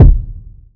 Kicks
Miss Me DuoKick.wav